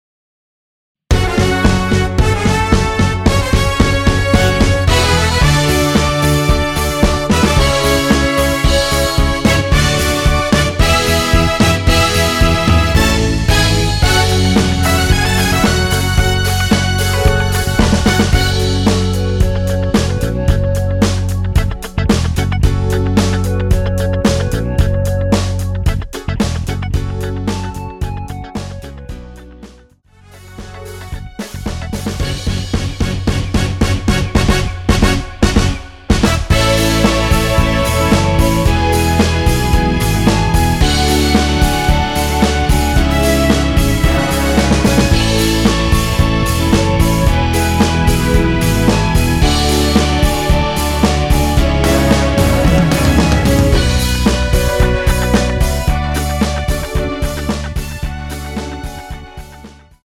원키에서(-1)내린 멜로디 포함된 MR입니다.(미리듣기 확인)
F#m
노래방에서 노래를 부르실때 노래 부분에 가이드 멜로디가 따라 나와서
앞부분30초, 뒷부분30초씩 편집해서 올려 드리고 있습니다.